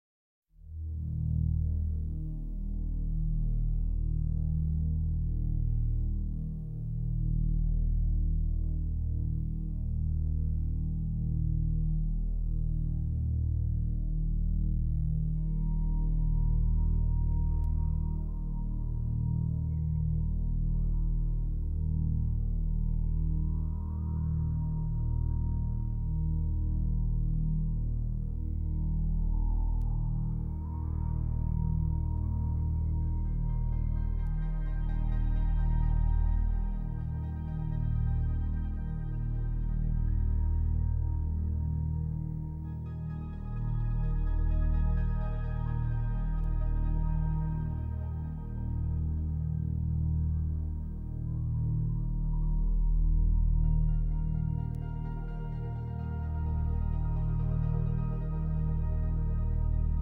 Атмосфера альбома поражает изысканностью.